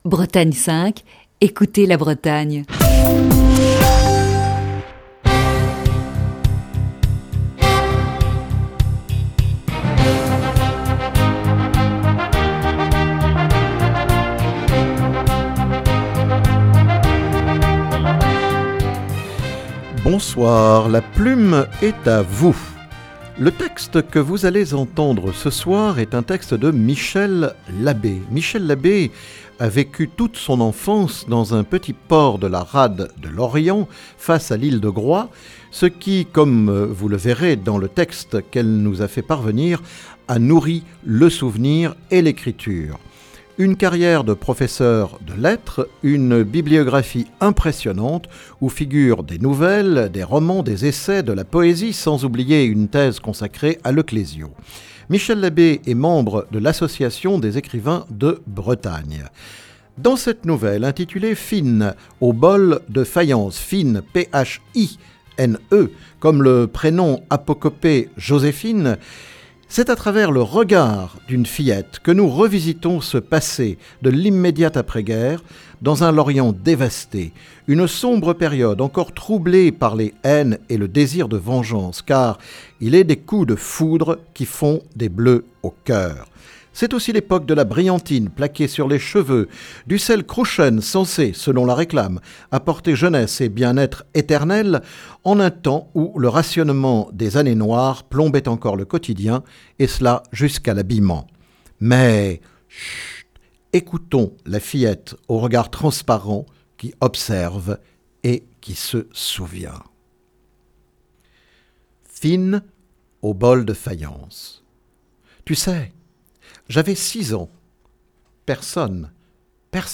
où il lisait deux textes